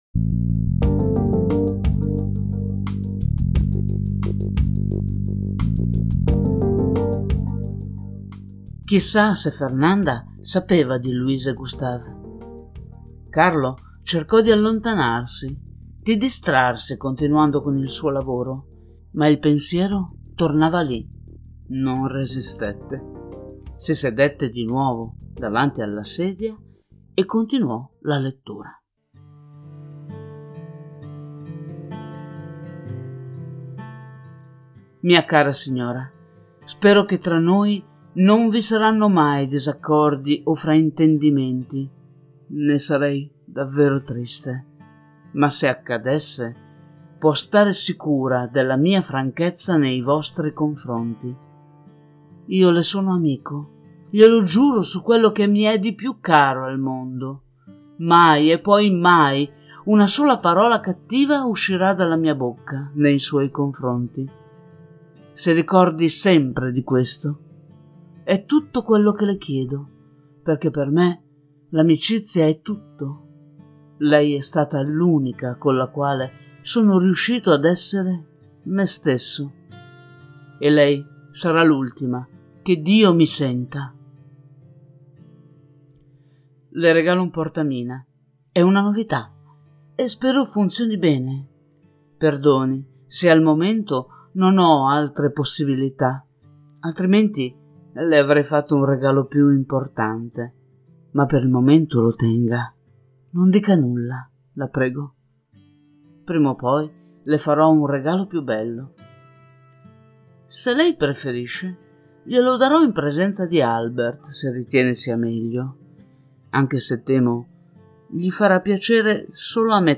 Musiche:
voce-secondo-episodio-musicato-freepd.mp3